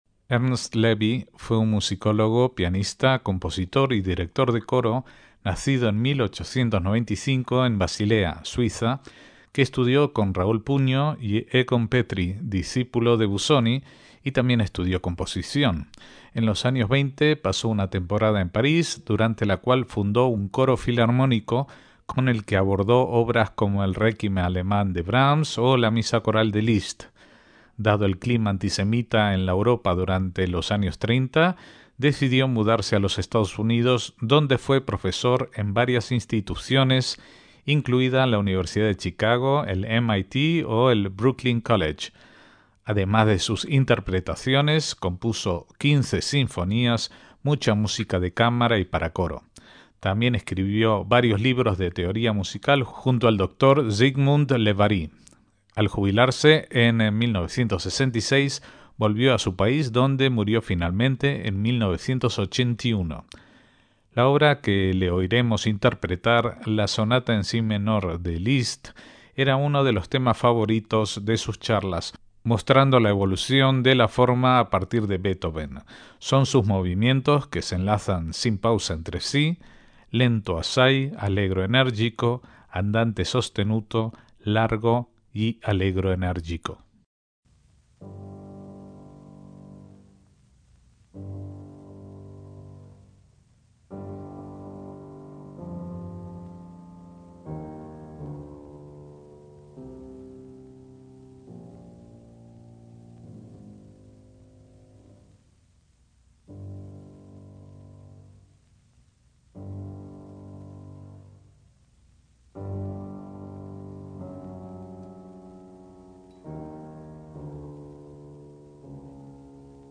MÚSICA CLÁSICA
pianista